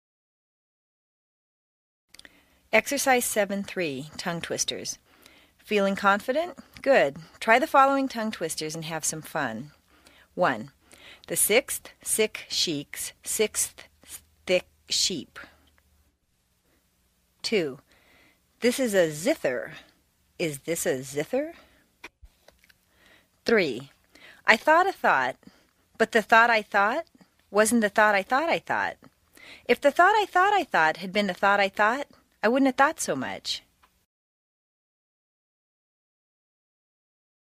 在线英语听力室美式英语正音训练第88期:第7章 练习3的听力文件下载,详细解析美式语音语调，讲解美式发音的阶梯性语调训练方法，全方位了解美式发音的技巧与方法，练就一口纯正的美式发音！